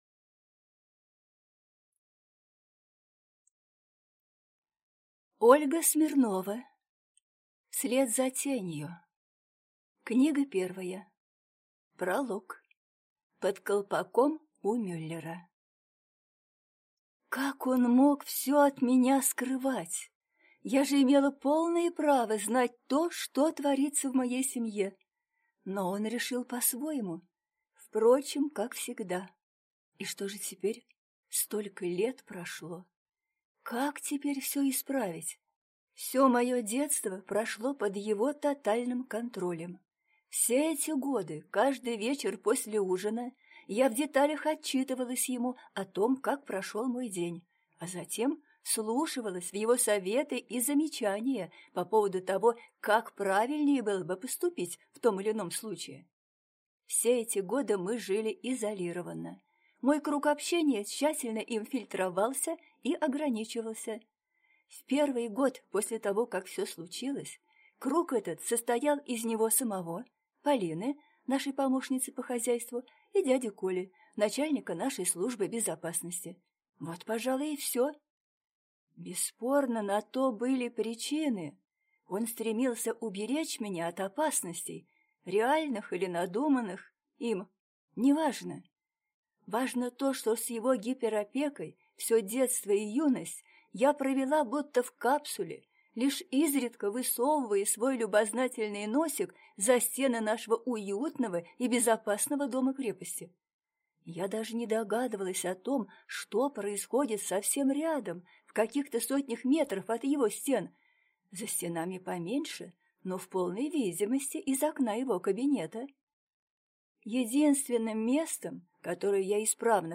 Аудиокнига Вслед за тенью. Книга первая | Библиотека аудиокниг